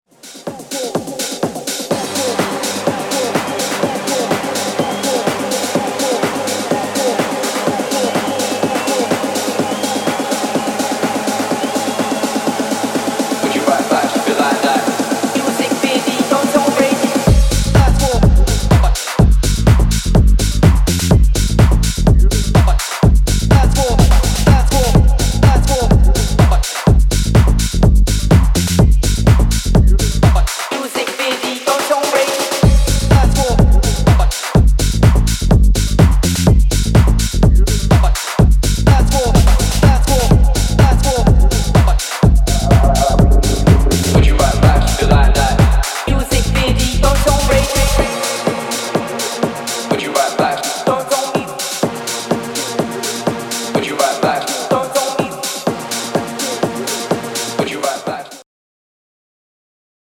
House, Tech House y Techno más bailable